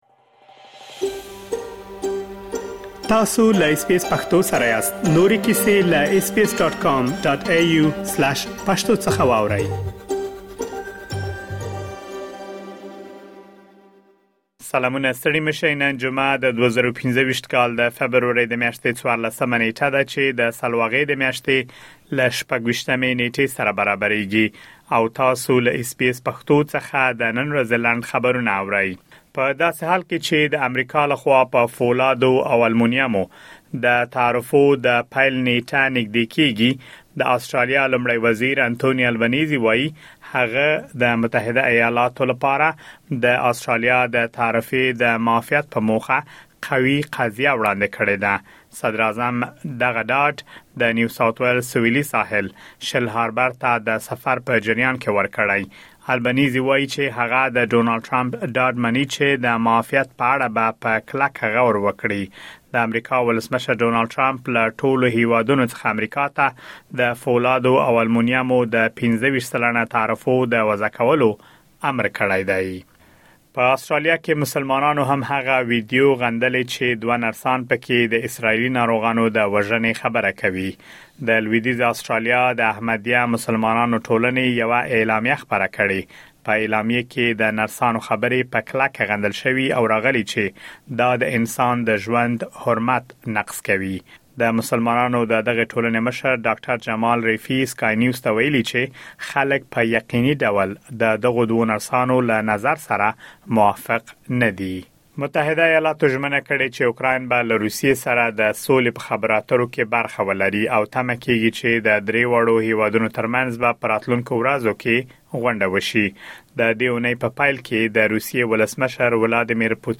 د اس بي اس پښتو د نن ورځې لنډ خبرونه | ۱۴ فبروري ۲۰۲۵